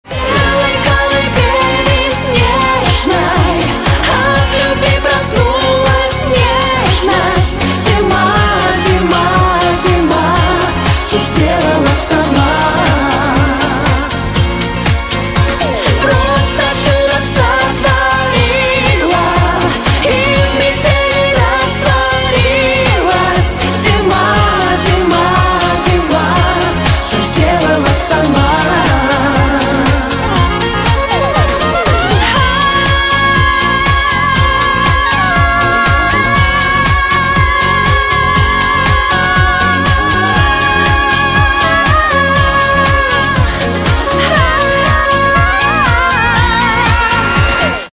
Отличная мелодия на рингтон под зимнее настроение